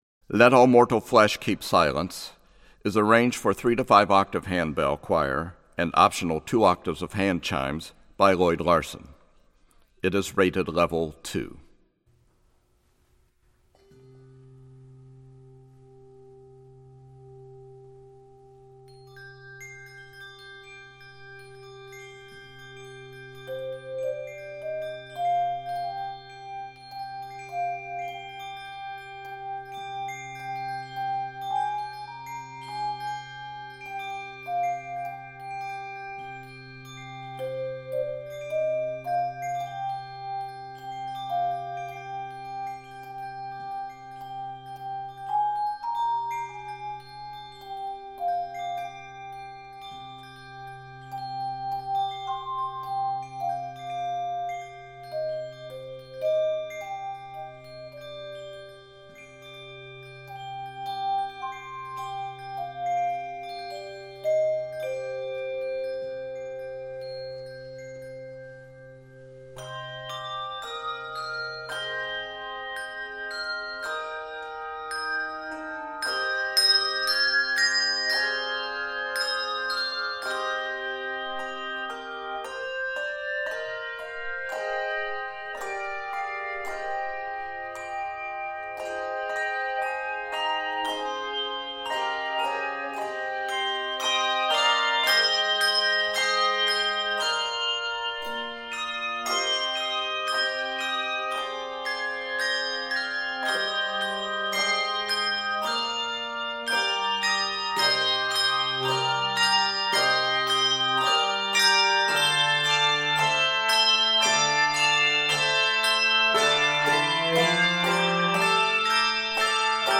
Keys of c minor and d minor.